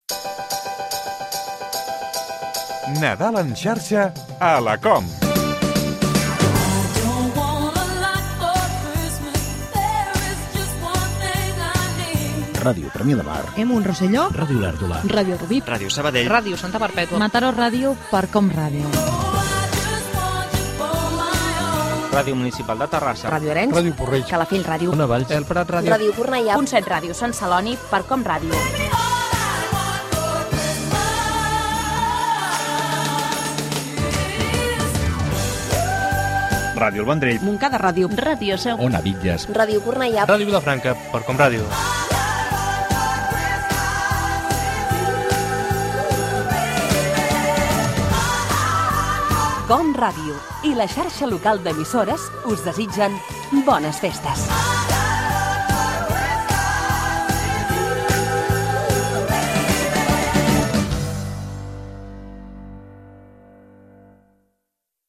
Dues promocions de "Nadal en xarxa a la COM" amb identificacions de diverses emissores de la Xarxa Local d'Emissores